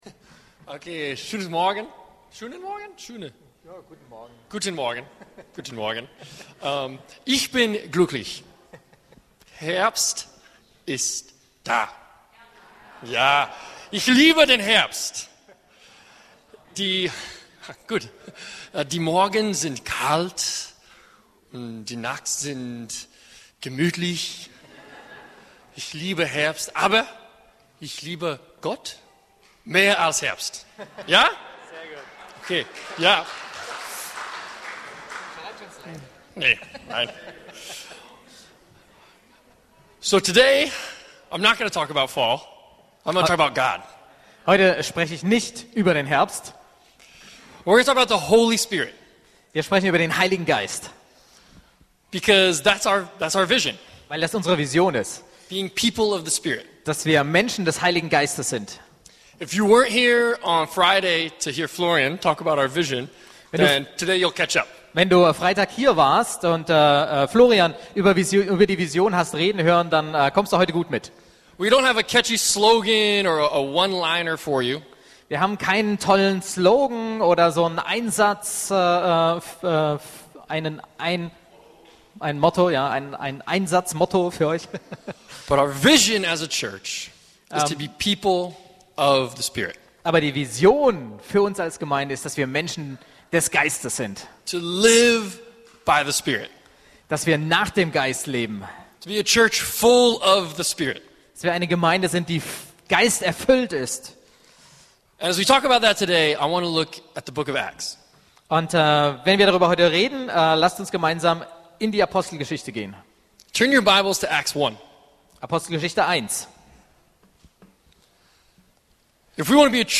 E-Mail Details Predigtserie